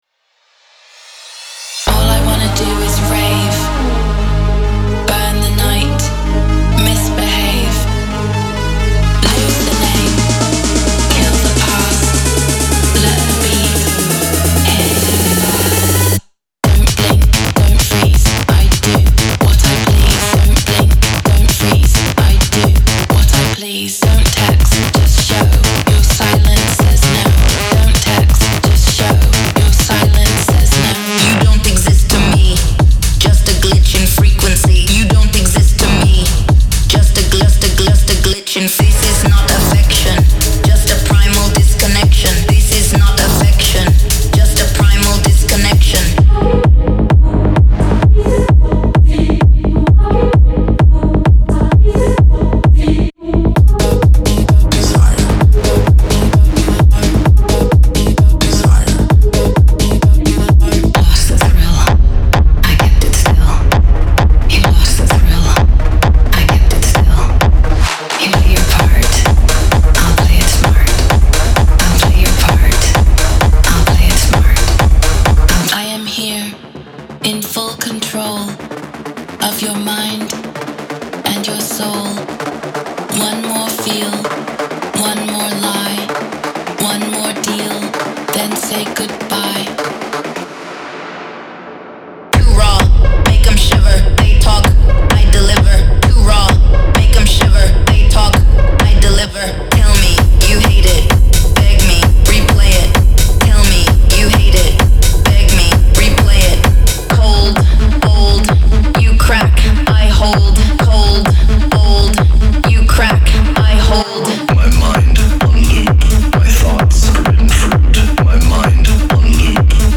Genre:Techno
ご注意：デモトラックは、本サンプルパックに含まれる音源のみで制作されています。
6 different vocal styles (4 female, 2 male)
130 bpm